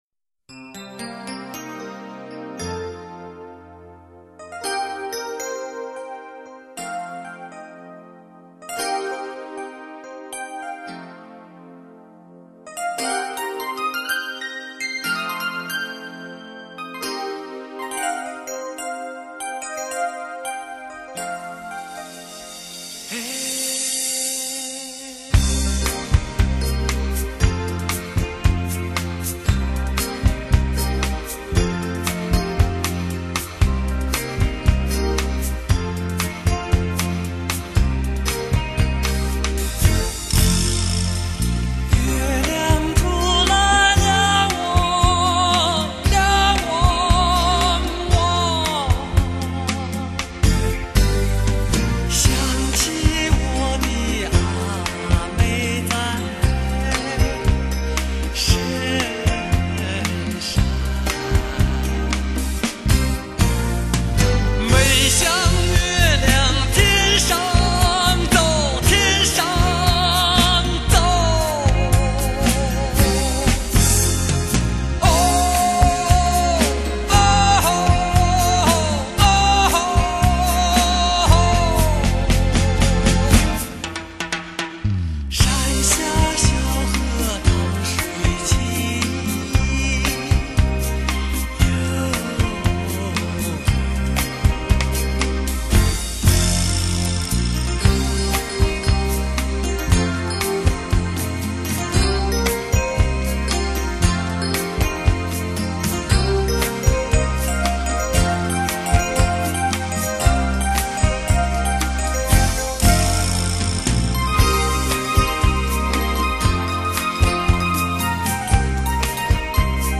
收录多首蒙古民歌小品 柔与刚完美结合